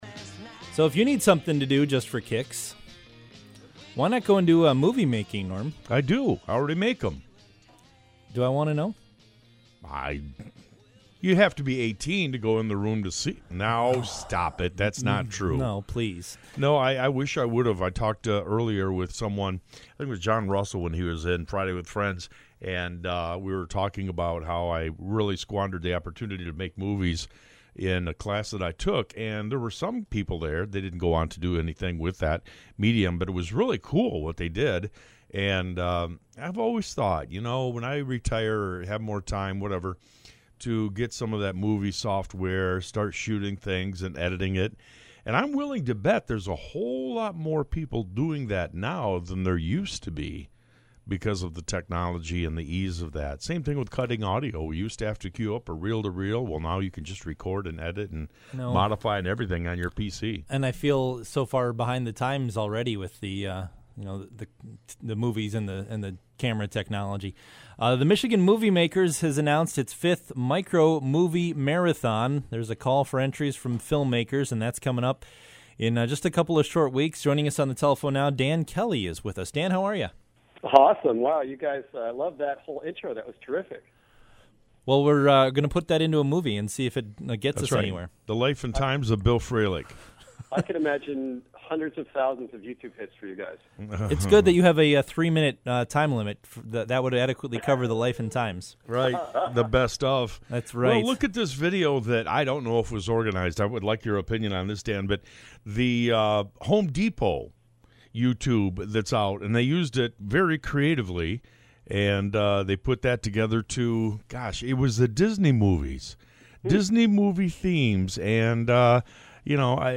Interview on WTCM talk